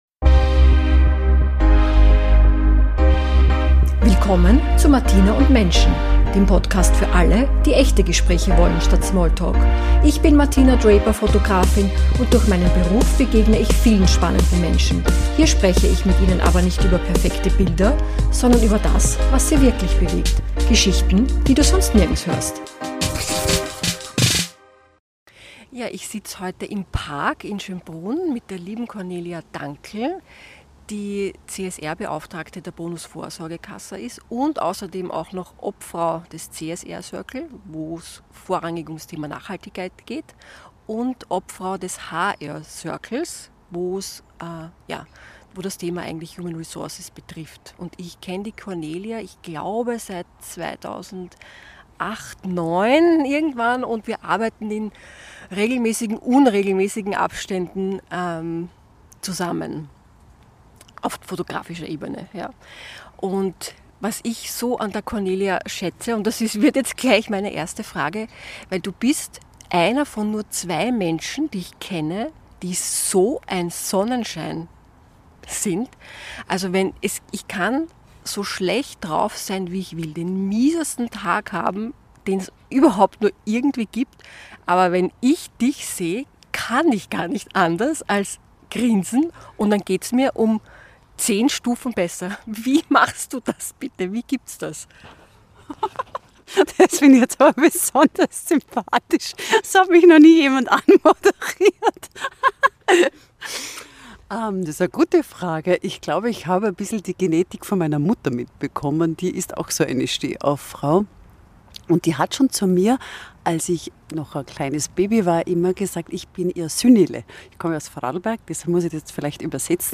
Eine Folge voller Lachen, Leichtigkeit und der Erinnerung daran, dass Plan B oft viel besser ist als Plan A. Mehr